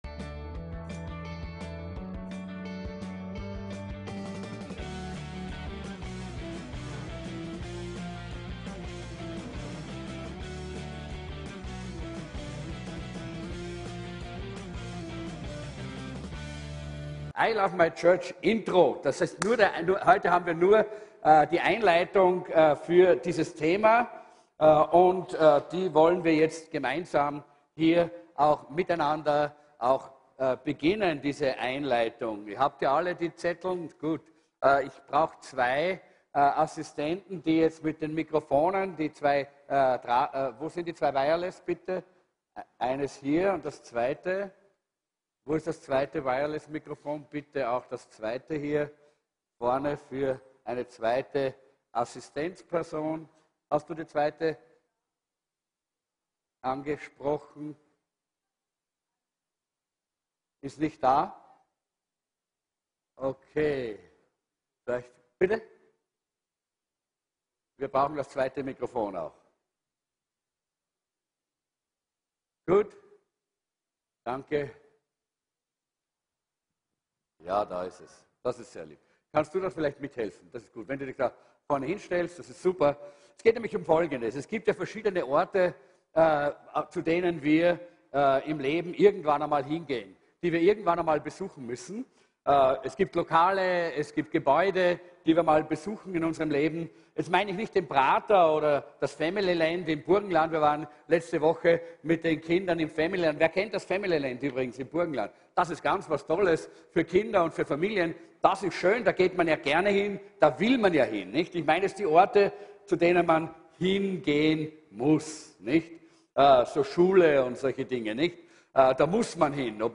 VCC JesusZentrum Gottesdienste (audio) Podcast